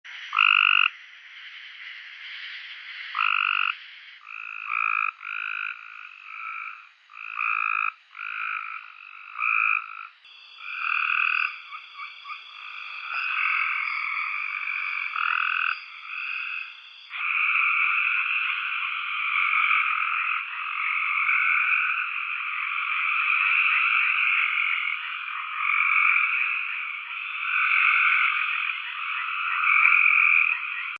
Cope-s.Gray.Treefrog.mp3